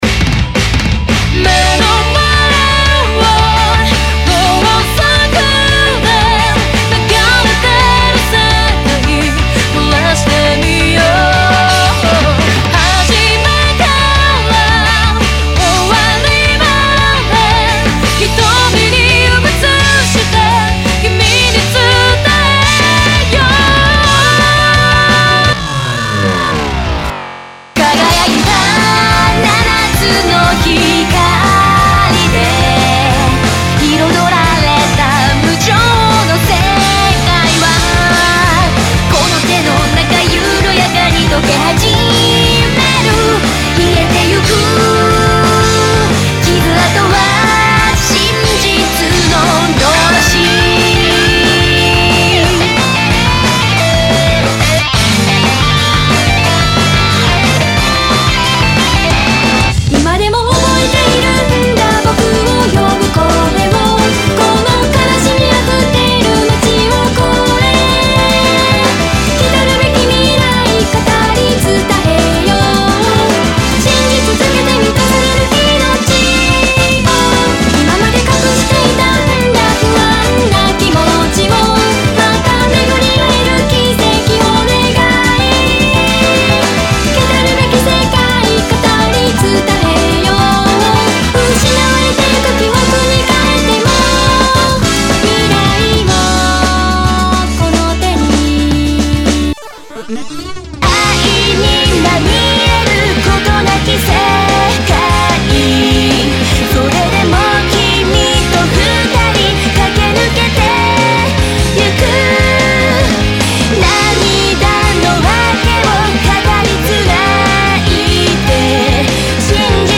(Off Vocal)